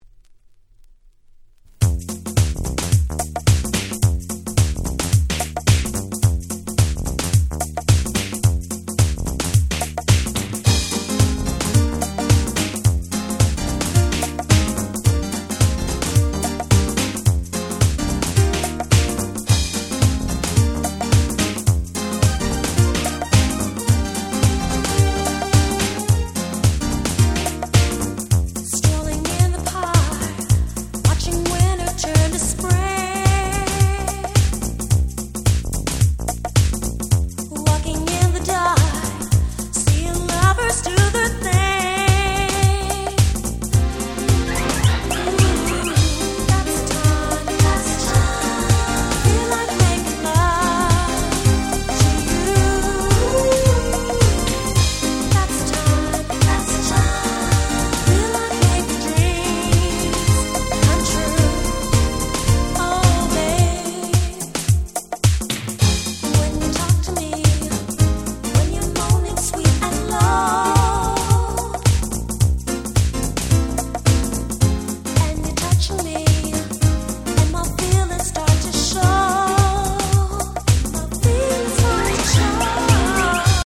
緩い四つ打ちの